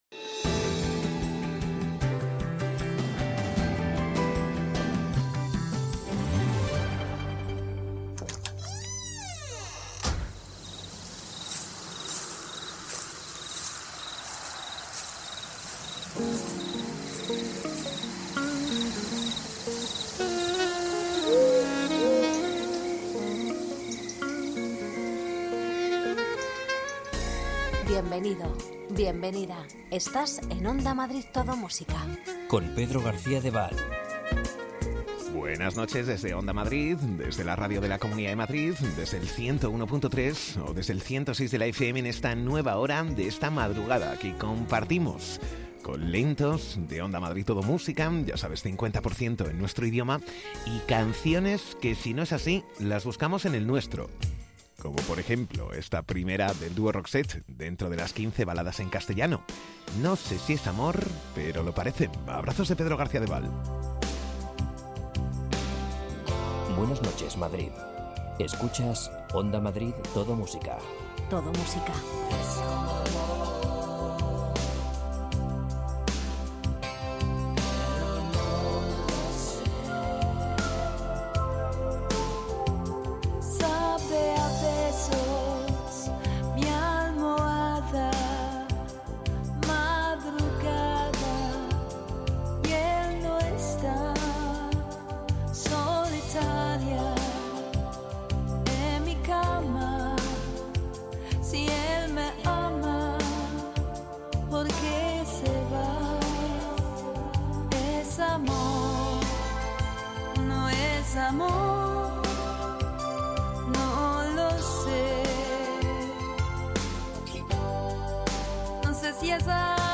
Ritmo tranquilo, sosegado y sin prisas.